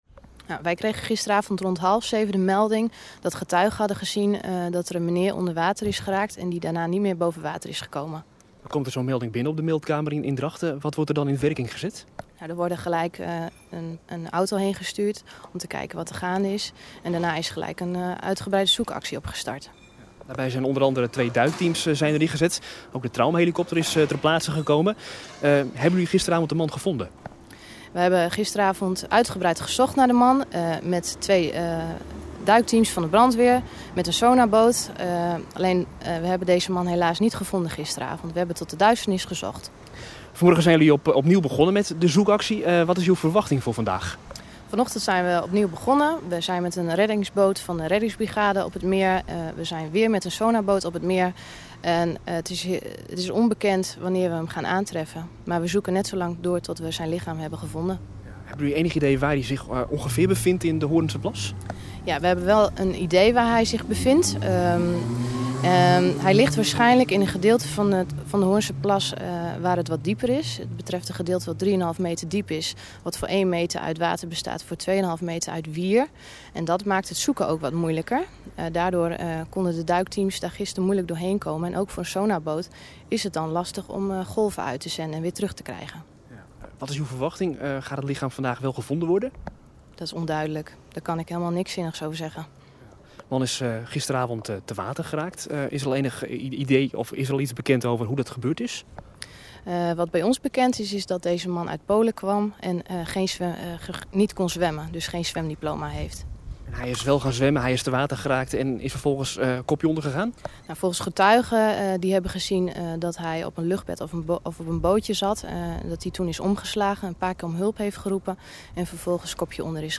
Verslaggever
met een ooggetuige..